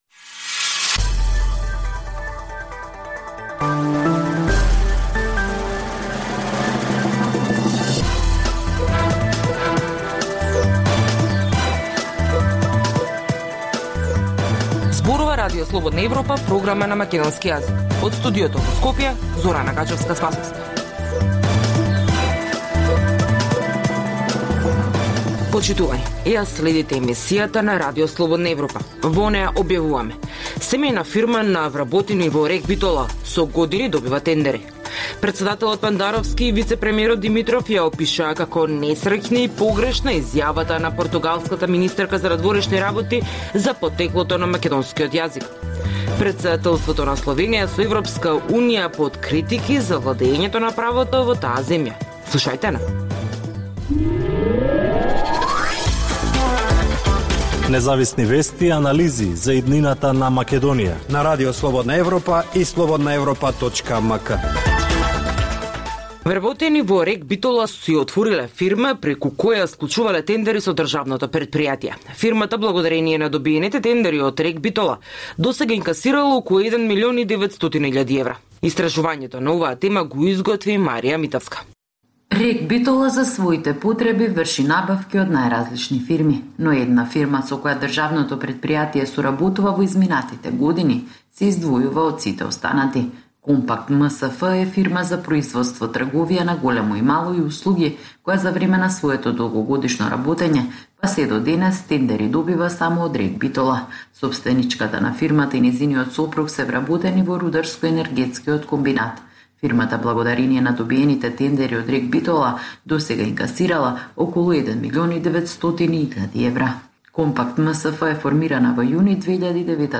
Утринска програма на Радио Слободна Европа од Студиото во Скопје. Во 15 минутната програма од понеделник до петок можете да слушате вести и прилози од земјата, регионот и светот. Во голем дел емисијата е посветена на локални настани, случувања и приказни од секојдневниот живот на граѓаните во Македонија.